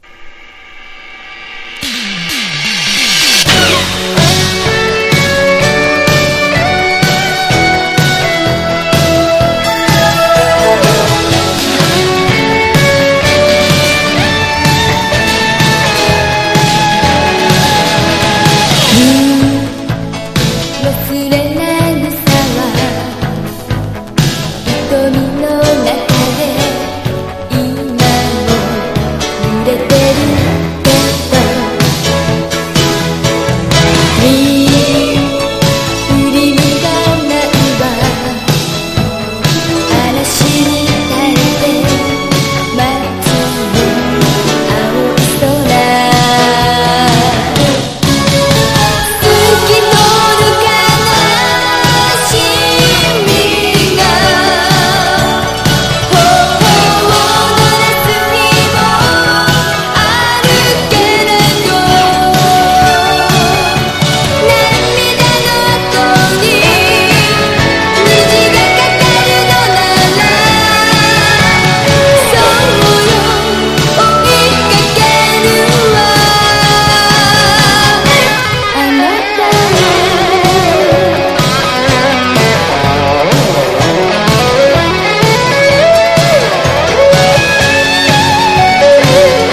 POP
ポピュラー# 70-80’S アイドル